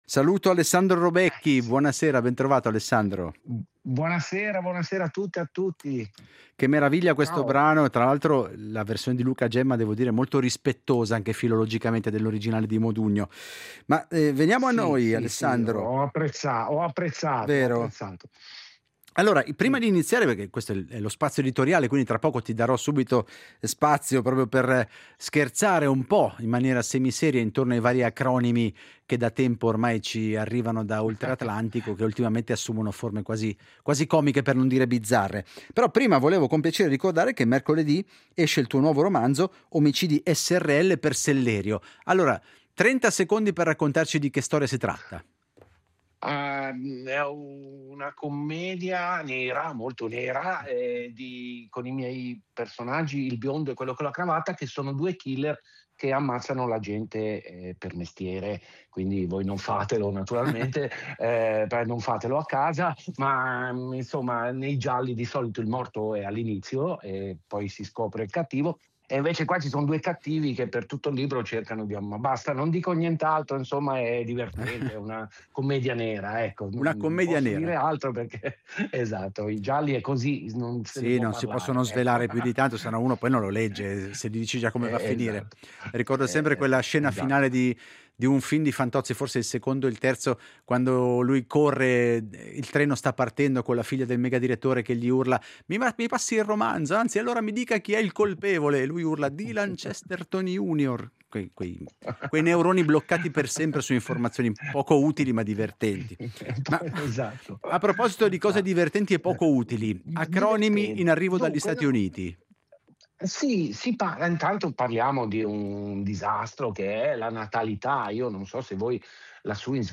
Alessandro Robecchi ha presentato il suo nuovo romanzo Omicidi srl. La conversazione si è poi spostata sul calo della natalità in Italia e sulla sua critica agli acronimi americani come “Dink”, “Zink” e “Dinkwad”, che a suo avviso semplificano eccessivamente problemi complessi. Robecchi definisce questo approccio una “tecnica della banalizzazione”, che ostacola una vera comprensione della realtà e favorisce un pensiero superficiale.